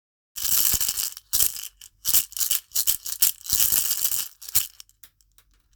種シェケレ(シェイカー) S
ひょうたんに種をつけたシェケレです。ビーズタイプより音がきつくなく素朴で抜けのよい音色が特徴。